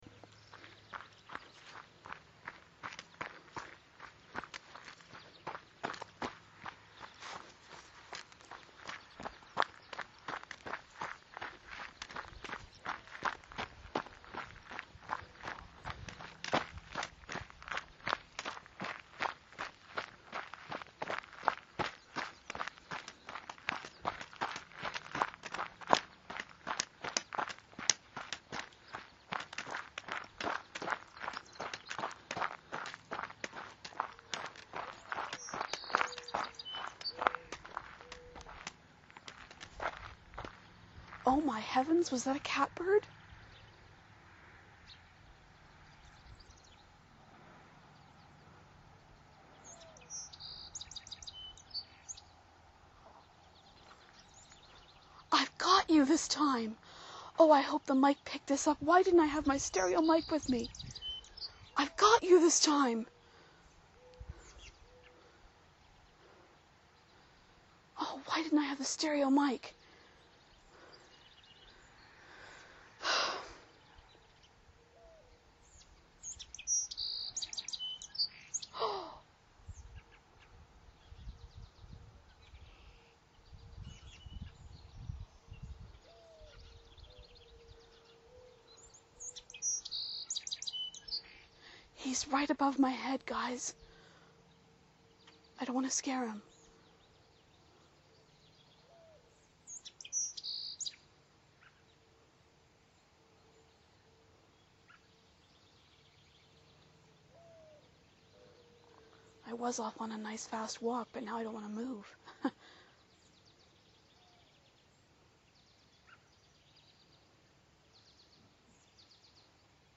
Join me as I take another walk down a country road - this time with some interesting results!